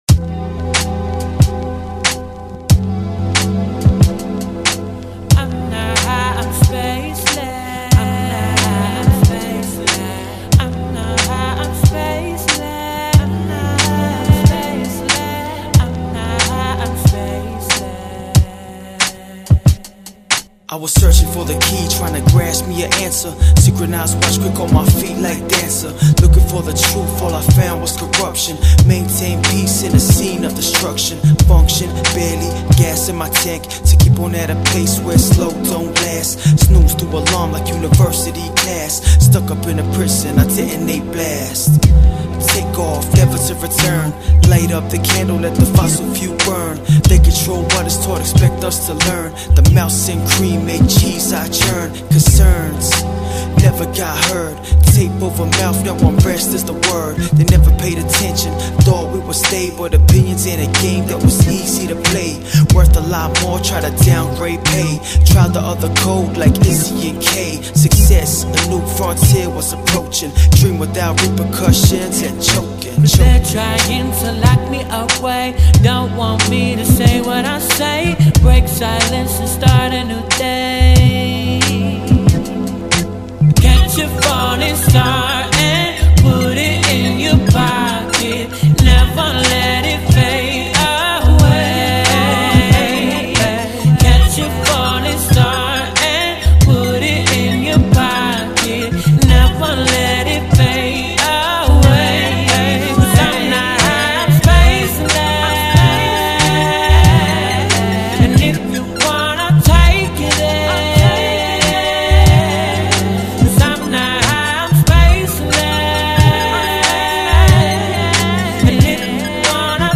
funky lil 10 track ep